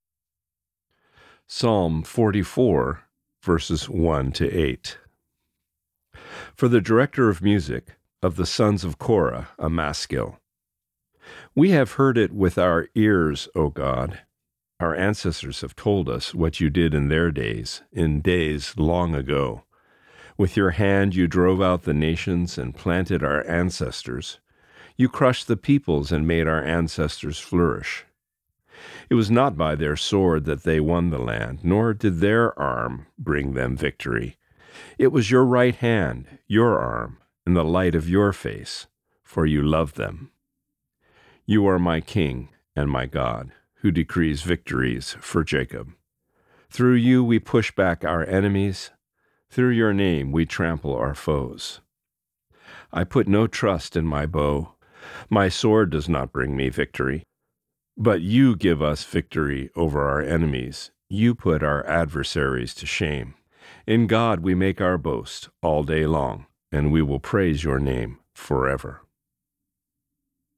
Reading: Psalm 44:1-8